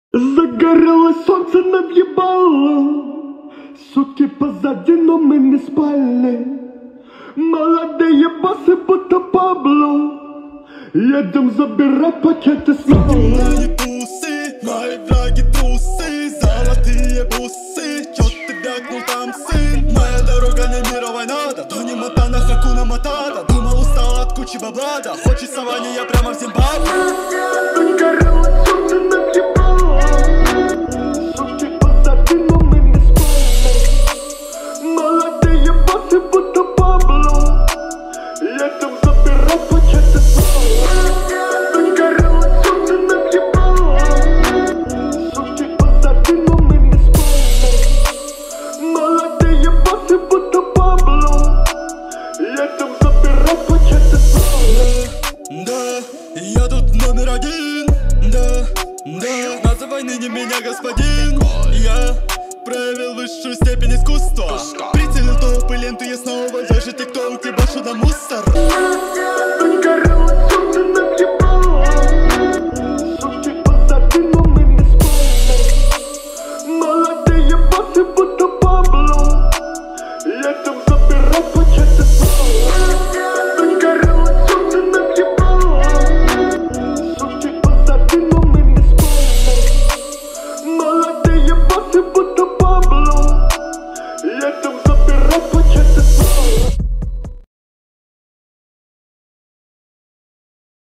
это яркая и энергичная композиция в жанре рэп